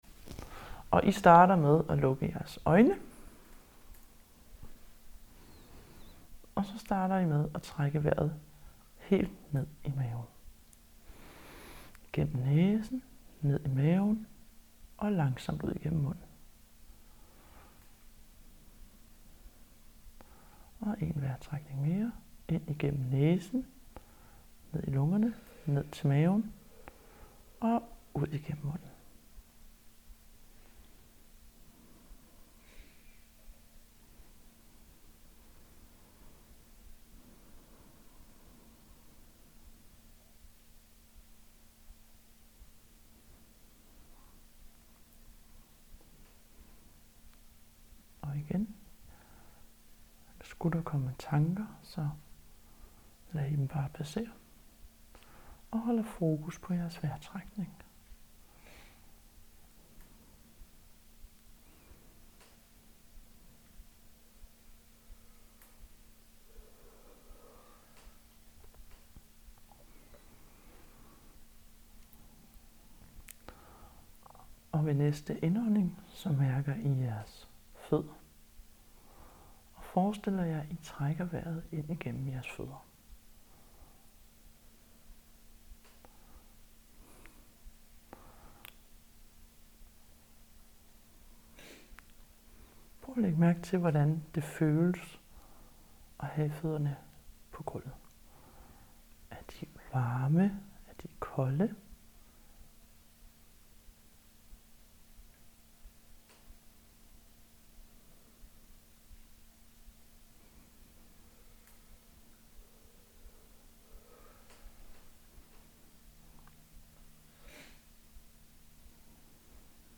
meditation på hænder og føder